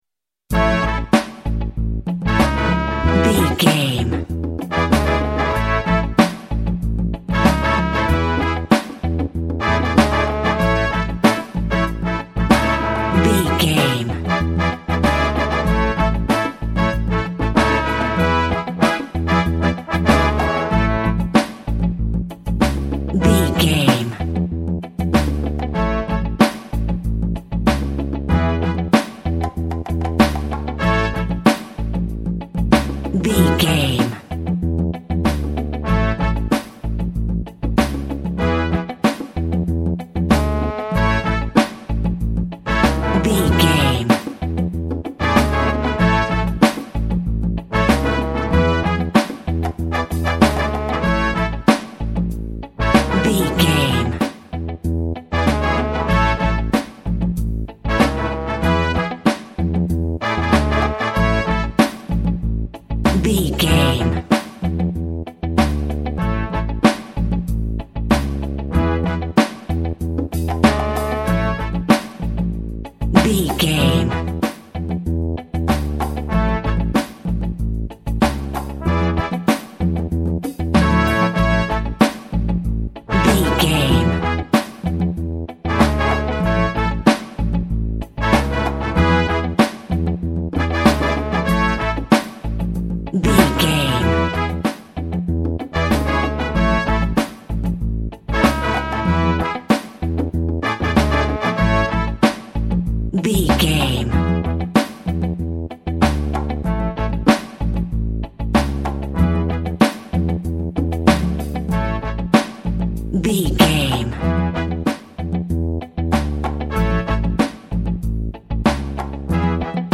Also with small elements of Dub and Rasta music.
Ionian/Major
tropical
drums
bass
guitar
piano
brass
pan pipes
steel drum